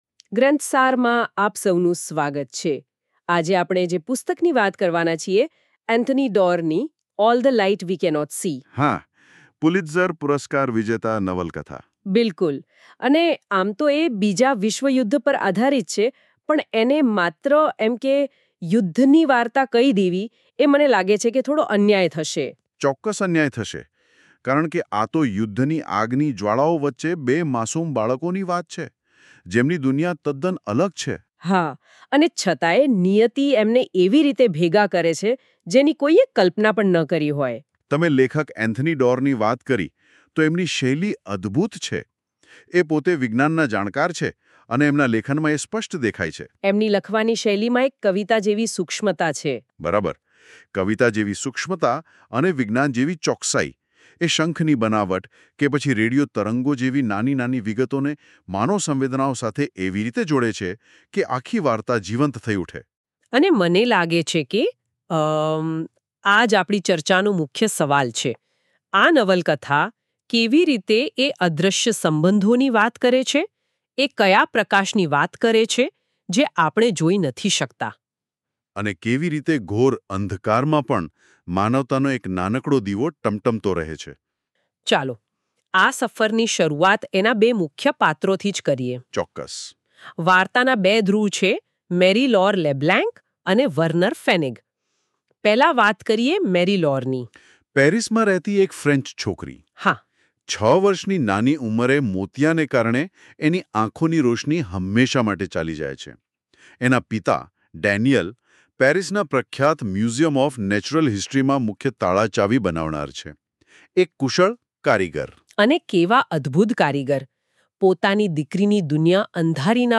Ekatra audio summary – Gujarati